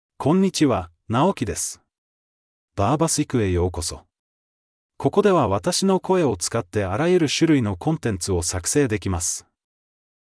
NaokiMale Japanese AI voice
Naoki is a male AI voice for Japanese (Japan).
Voice sample
Listen to Naoki's male Japanese voice.
Male
Naoki delivers clear pronunciation with authentic Japan Japanese intonation, making your content sound professionally produced.